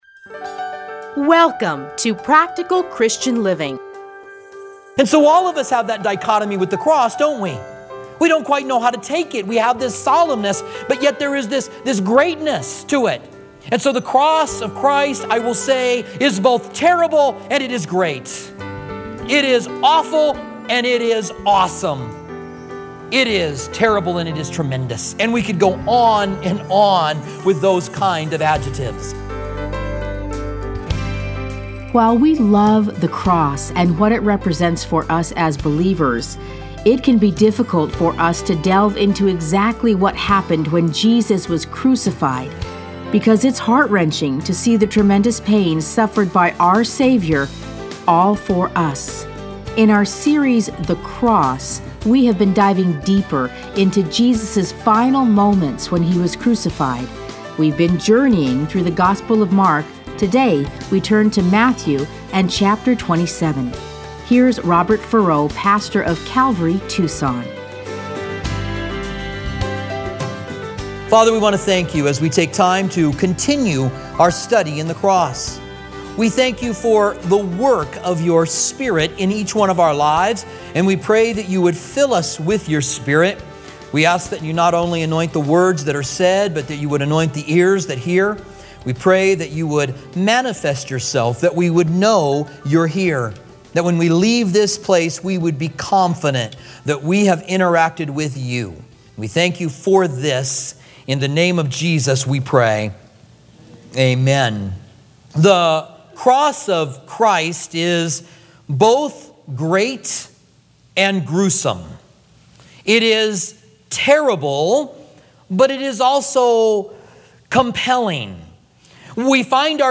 Listen to a teaching from Matthew 15:27:25, 50-53.